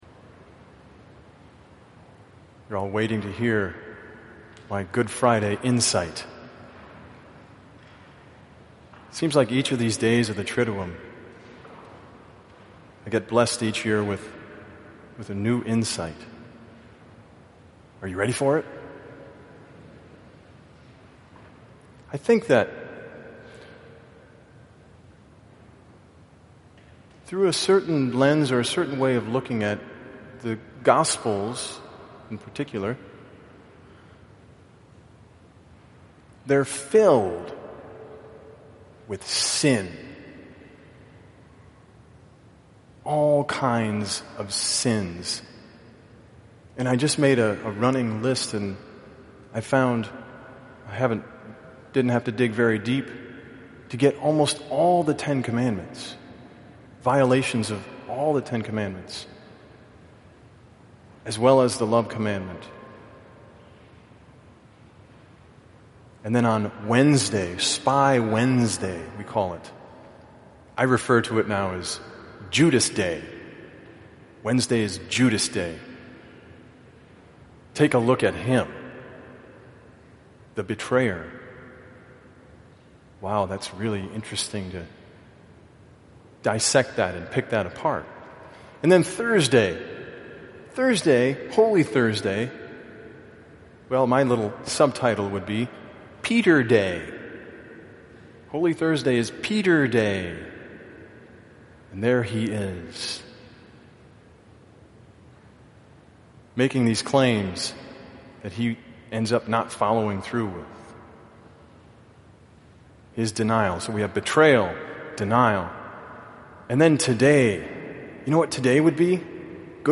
POSTS: Video Commentaries & Homilies (Audio)
Good Friday 2015 (evening service)